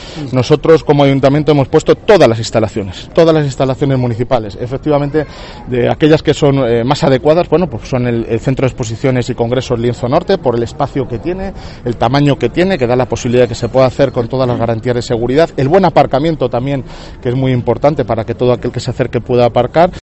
Alcalde sobre la vacunación masiva
(Escuchar audio de Jesús Manuel Sánchez Cabrera)